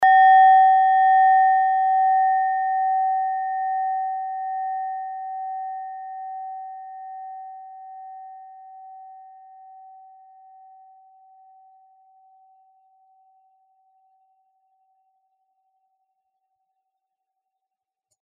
Klangschale Nepal Nr.13
Klangschale-Gewicht: 580g
Klangschale-Durchmesser: 11,2cm
(Ermittelt mit dem Filzklöppel)
klangschale-nepal-13.mp3